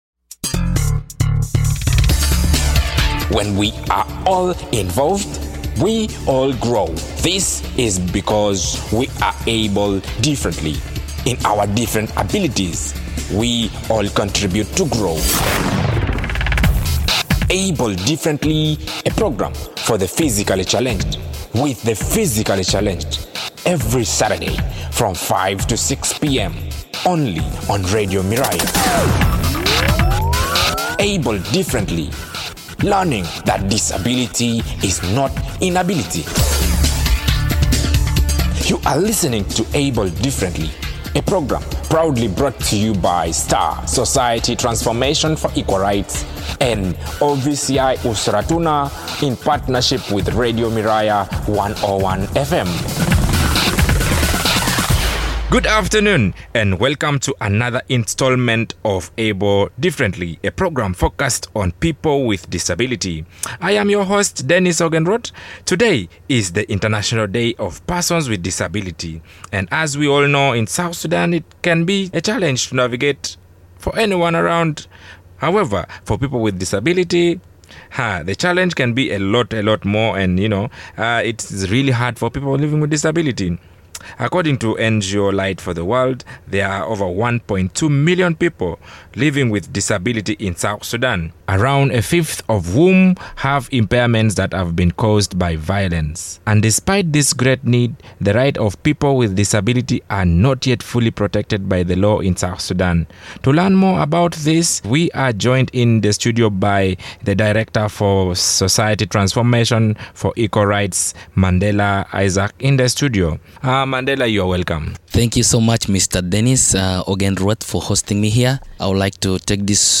Despite this great need the rights of people living with disabilities are not yet fully protected by law in South Sudan. Listen to the full discussion